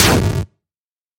Звуки получения урона
Звучание старой игры